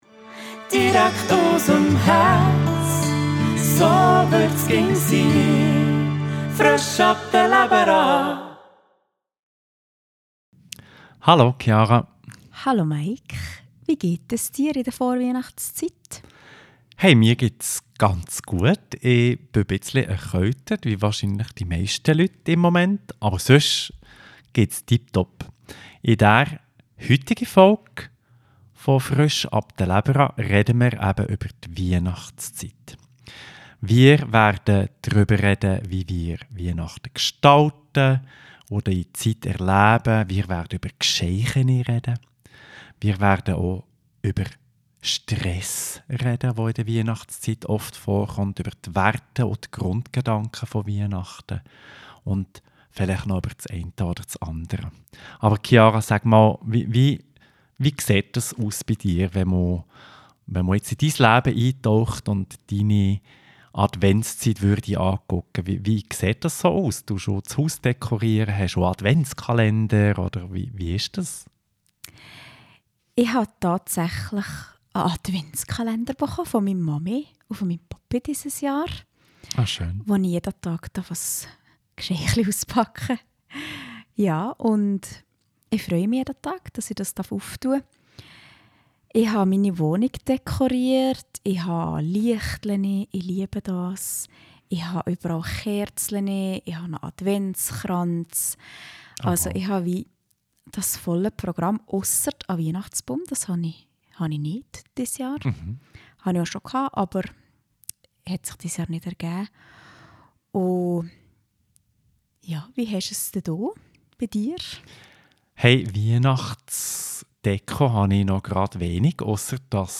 im Gspräch